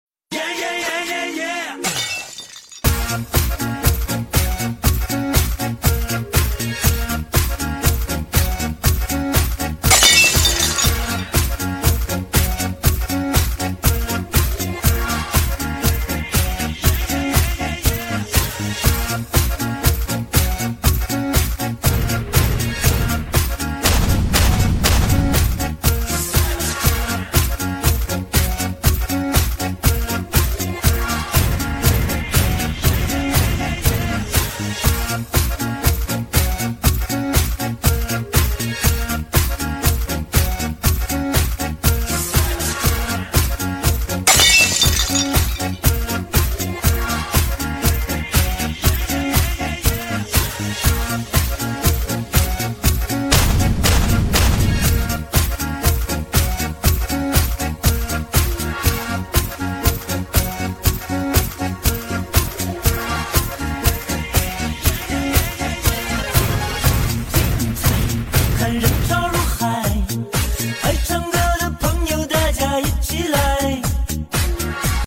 Doomsday Charm Hellfire Machine Gun sound effects free download